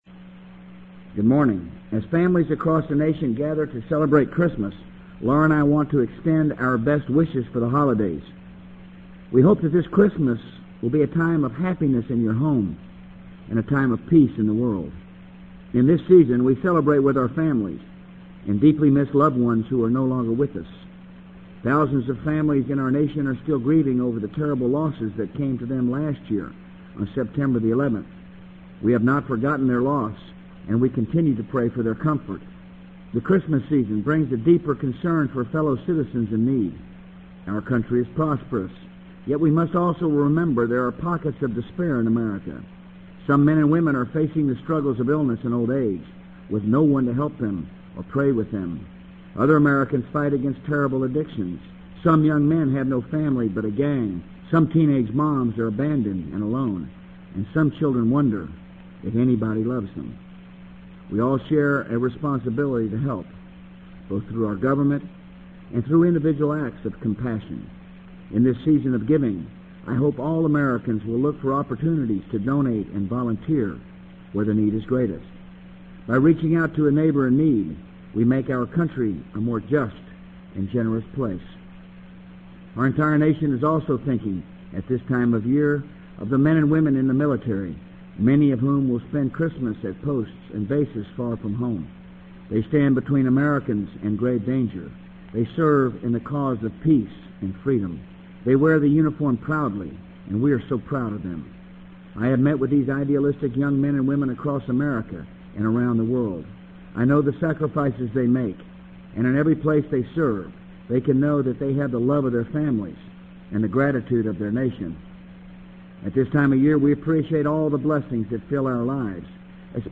【美国总统George W. Bush电台演讲】2002-12-21 听力文件下载—在线英语听力室